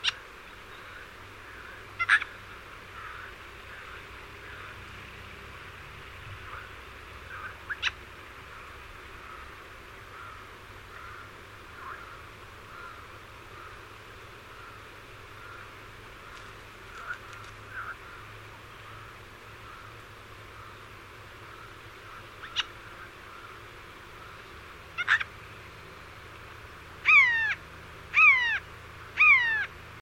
Geai des chênes - Mes zoazos
Il est connu pour son cri perçant et sa capacité à imiter d’autres sons. Habitant des forêts mixtes et des parcs, il se nourrit principalement de glands, de fruits, d’insectes et occasionnellement de petits vertébrés.
geai-des-chenes.mp3